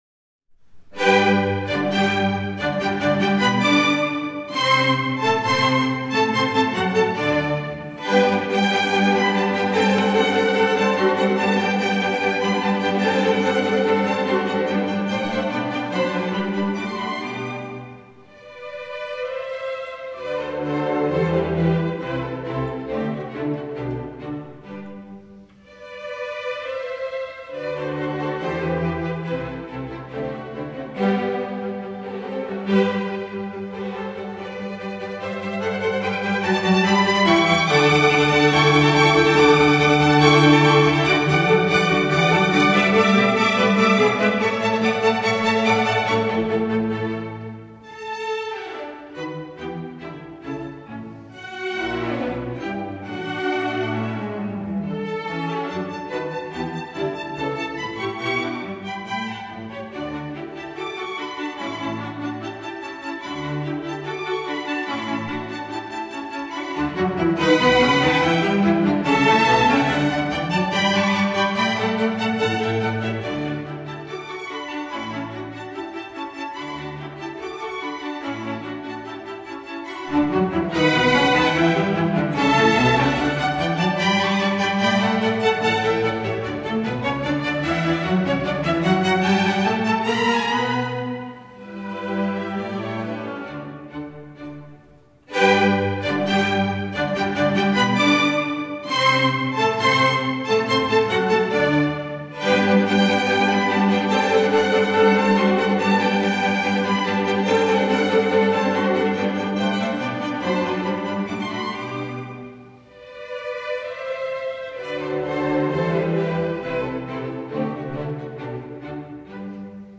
Serenade-in-G-Major-K.-525-Eine-kleine-Nachtmusik-I.-Allegro.mp3